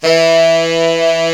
Index of /90_sSampleCDs/Giga Samples Collection/Sax/HARD SAX
TENOR HARD E.wav